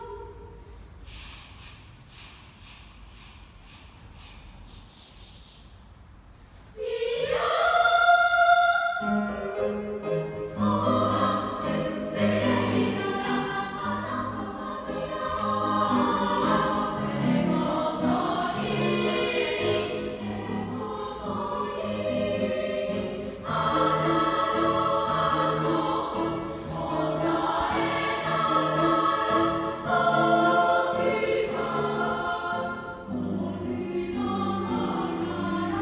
東渡り廊下から美しい歌声が！（合唱部練習風景）